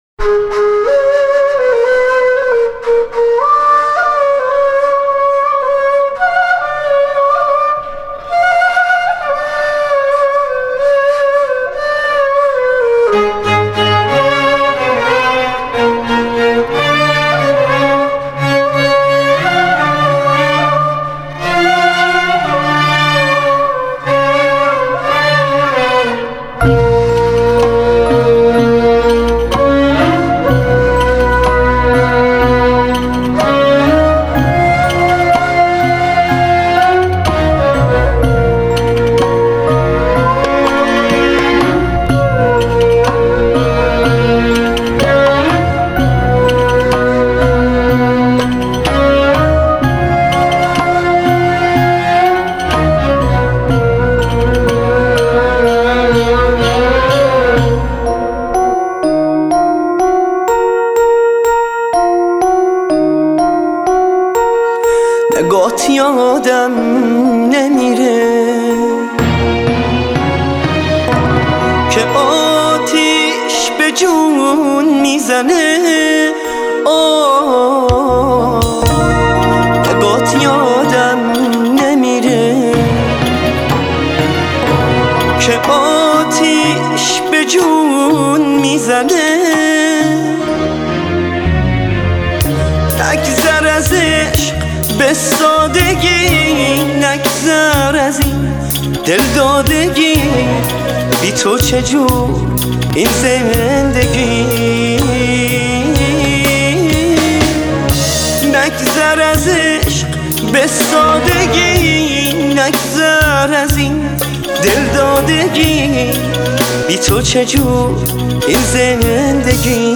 آهنگ قدیمی
آهنگ غمگین آهنگ ترکی